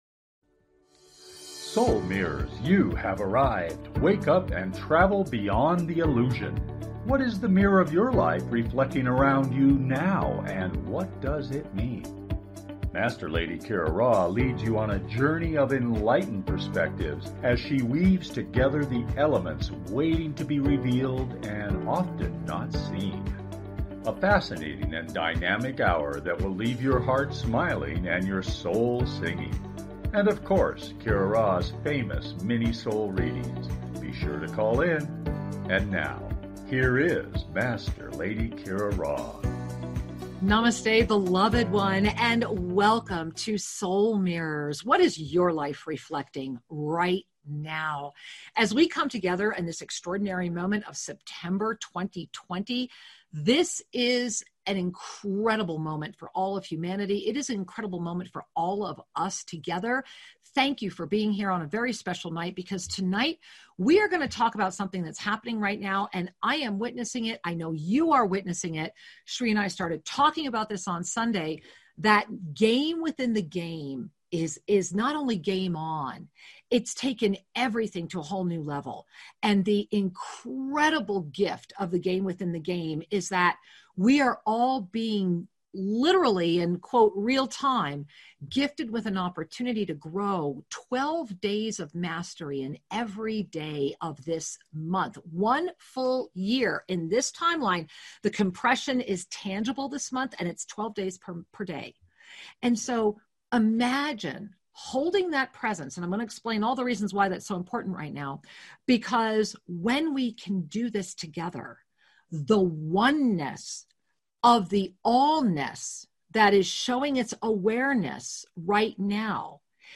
Talk Show Episode, Audio Podcast
Lively, entertaining, and refreshingly authentic, the hour goes quickly!